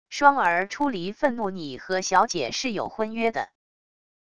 霜儿出离愤怒――你和小姐是有婚约的wav音频